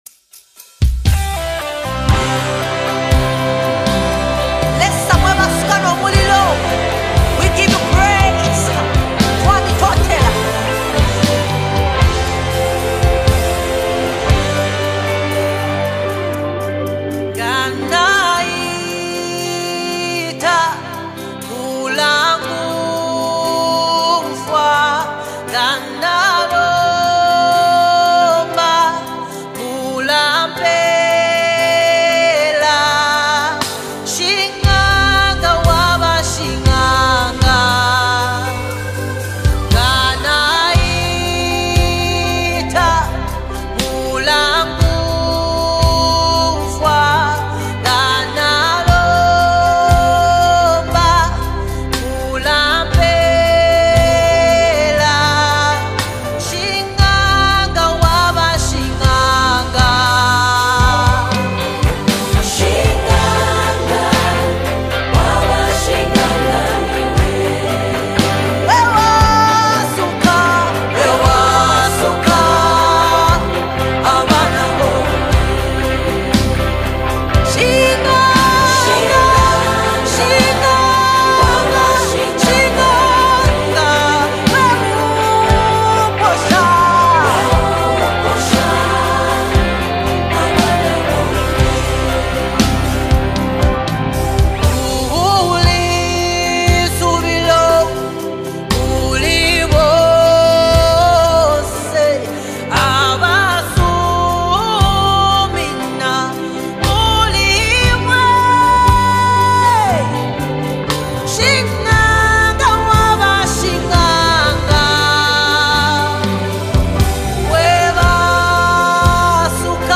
Zambia's gospel music sensation